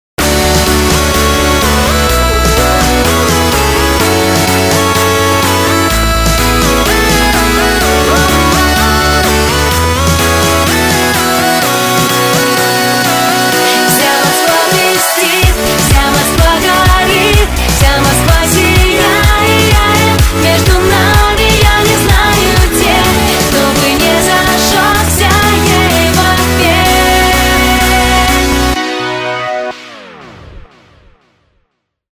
Клубные [95]